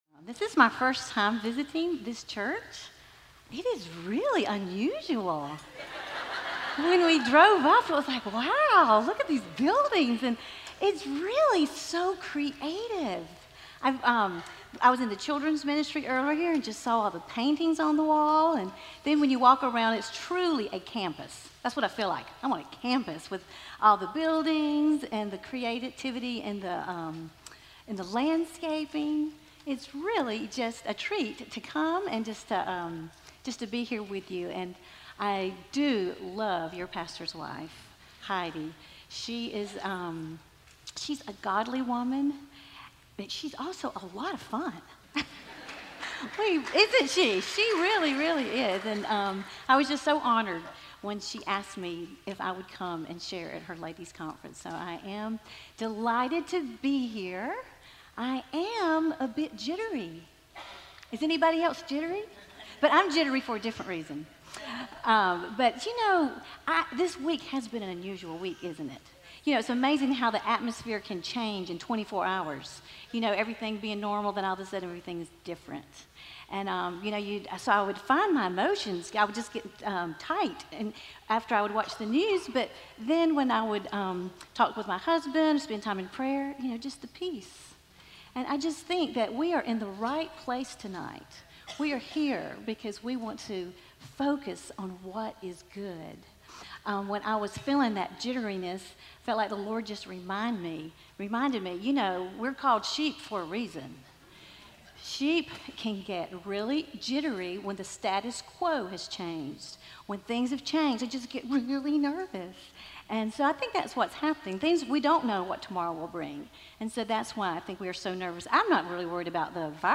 Womens Conference 2020